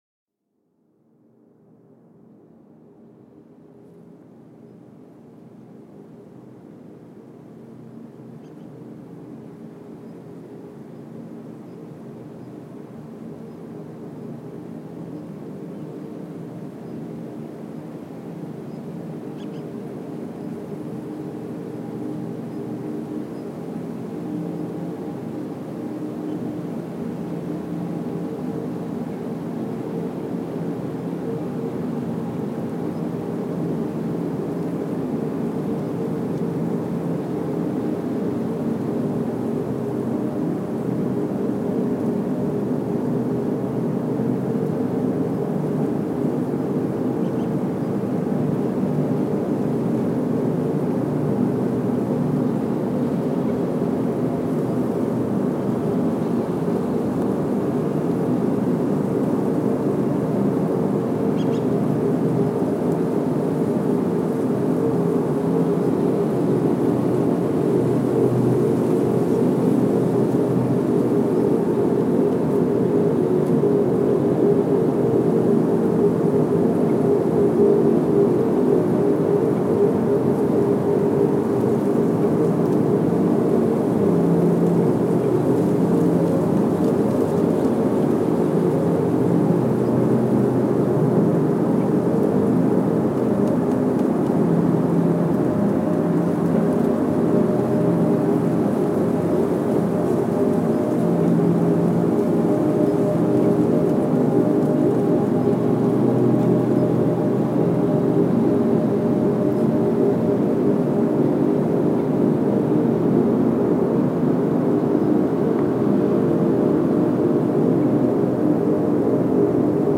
Genre: Ambient/Field Recording.